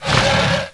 Heroes3_-_Azure_Dragon_-_DefendSound.ogg